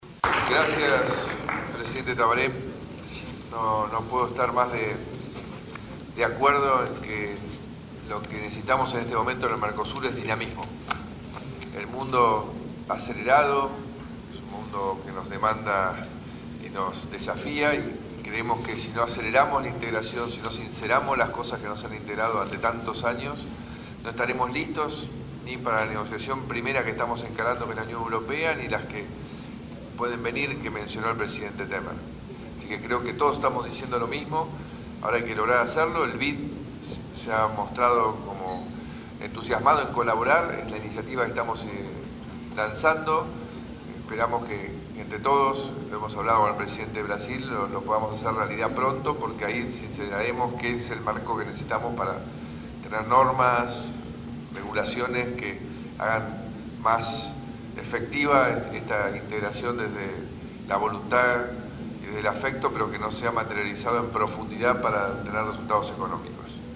Al finalizar la oratoria del presidente Tabaré Vázquez, el mandatario argentino, Mauricio Macri, coincidió en que el Mercosur necesita un mayor dinamismo para trabajar en pos de los objetivos del acuerdo regional. “Ahora hay que lograr hacerlo y el BID se ha mostrado interesado en colaborar con la iniciativa que hemos lanzado”, dijo durante la Cumbre de presidentes que se desarrolla en Mendoza, Argentina.